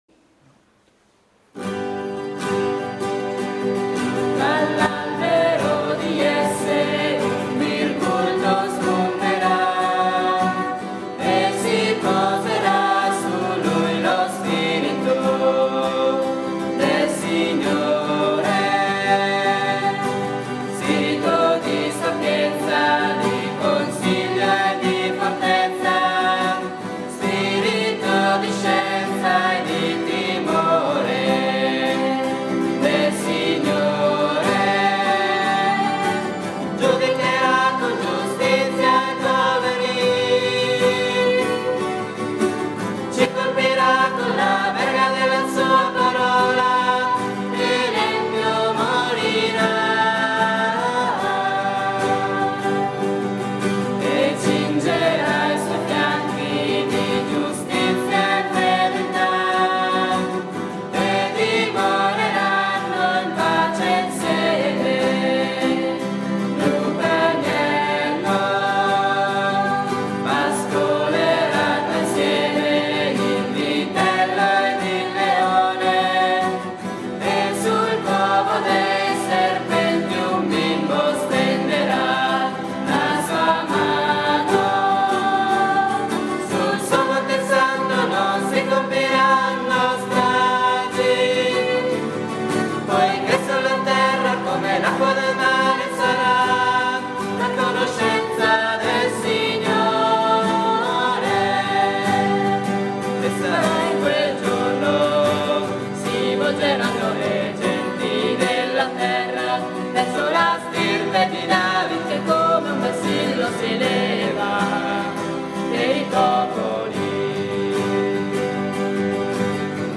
Vi suggerisco un canto di Natale che mi piace molto.